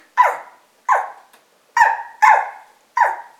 Aboiements-chien-Scott-DSC_0074.mp3